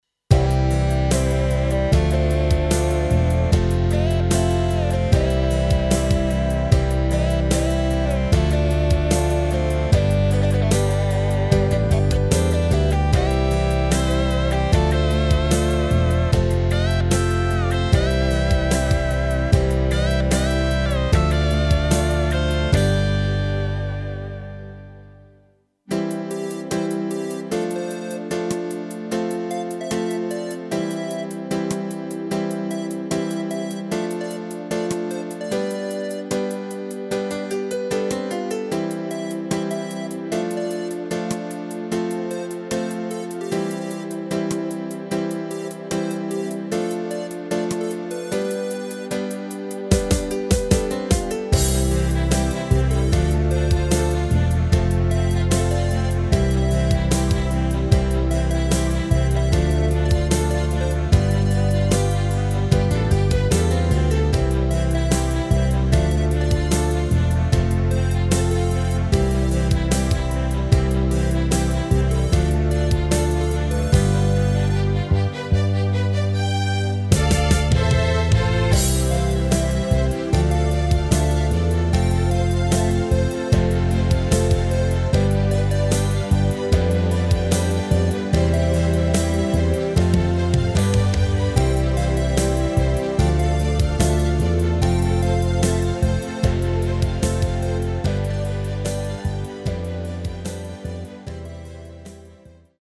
Rubrika: Vánoční písně, koledy
HUDEBNÍ PODKLADY V AUDIO A VIDEO SOUBORECH